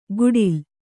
♪ guḍil